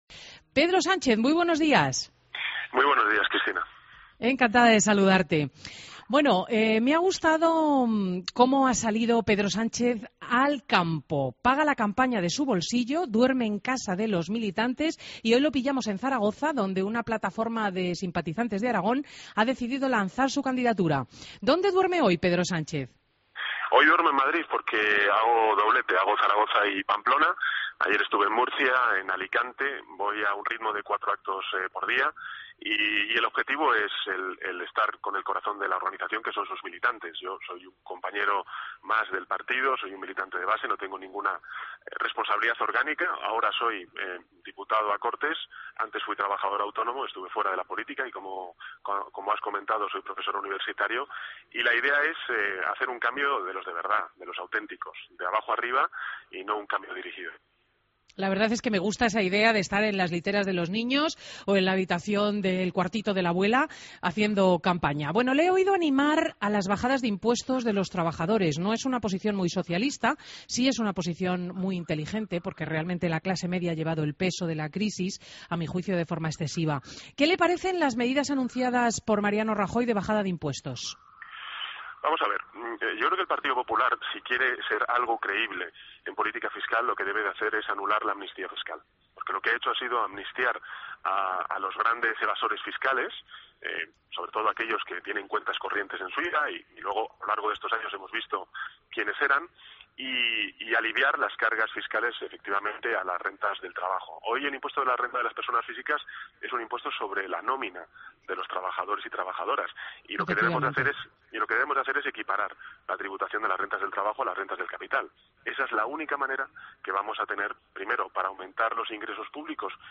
Entrevista a Pedro Sánchez, precandidato Sec. General PSOE